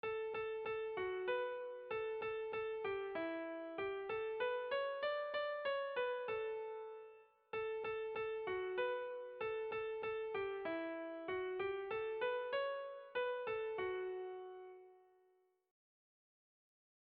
Sentimenduzkoa
Lauko handia (hg) / Bi puntuko handia (ip)
A1A2